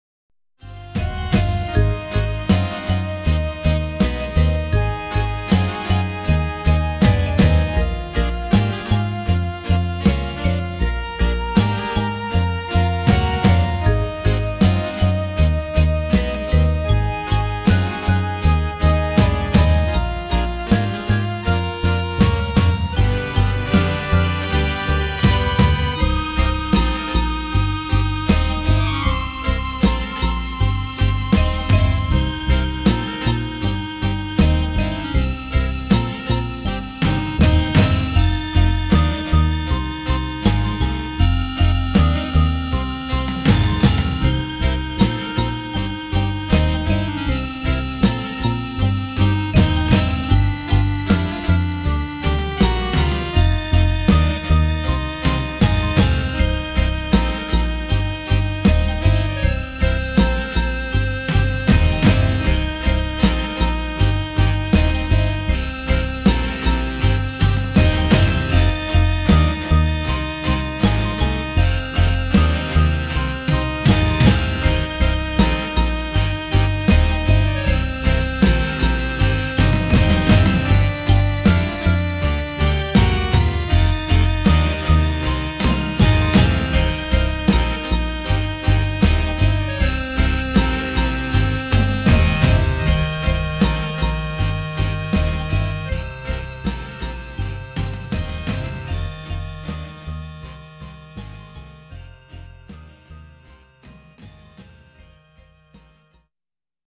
A sophisticated, soulful, orchestral cocktail on the Rocks,
where style and substance combine in an uproar of musicality